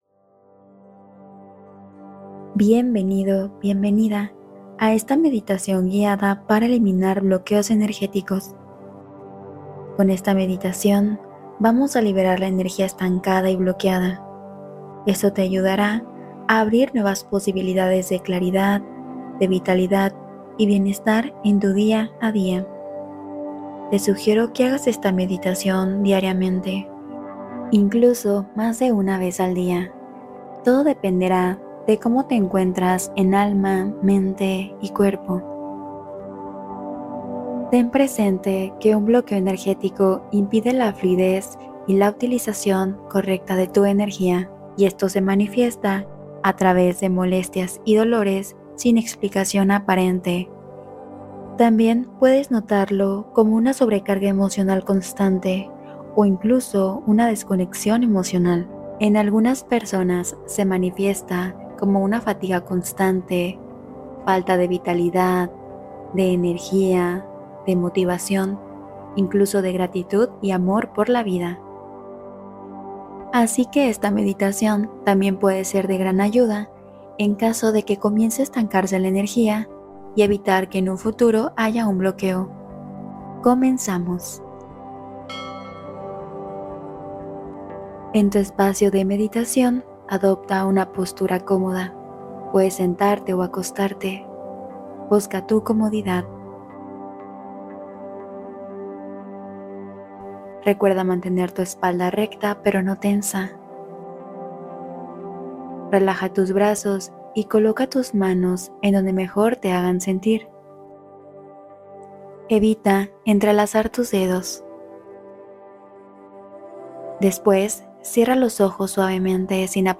Libera Bloqueos Energéticos: Meditación de Sanación